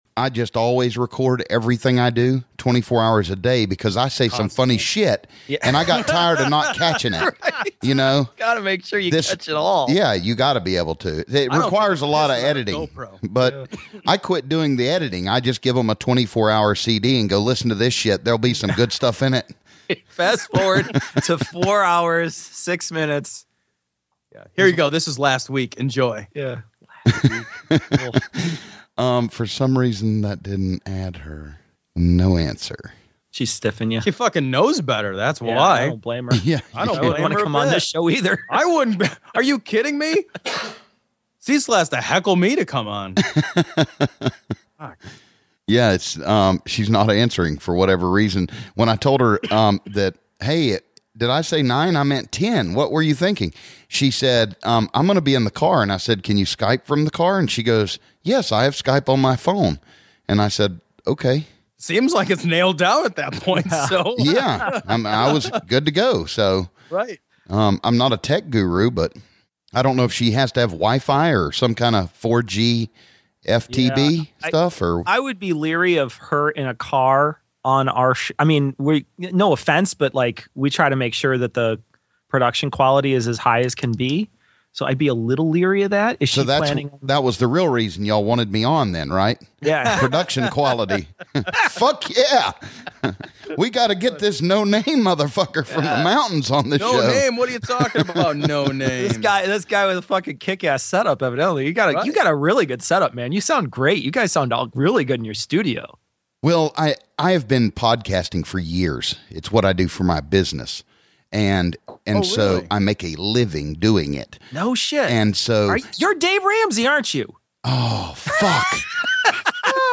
Some of it is just chit chat, but we thought it was funny enough to be an extra. The audio quality is ok-ish.